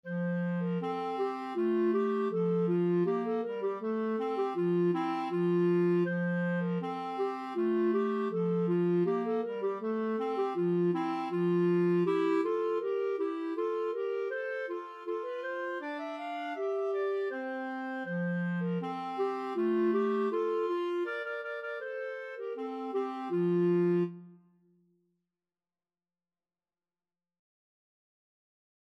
4/4 (View more 4/4 Music)
Very Fast =80
Clarinet Duet  (View more Easy Clarinet Duet Music)